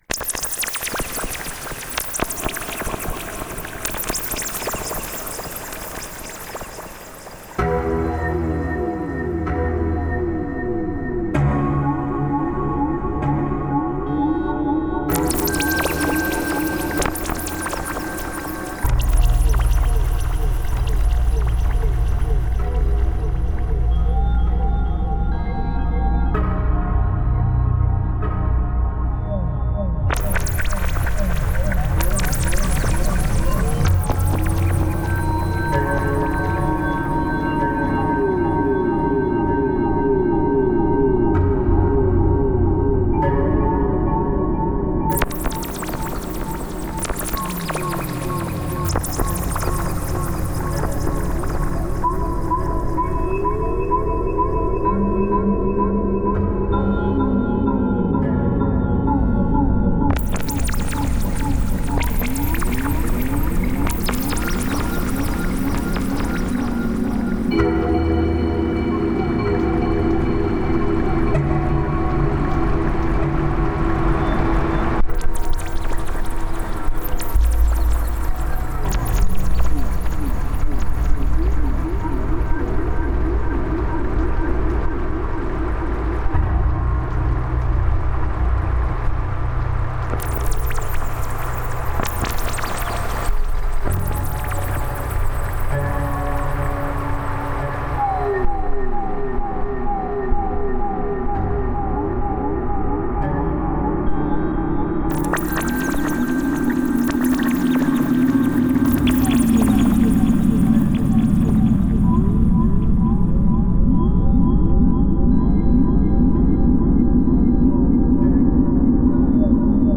Genre: Industrial.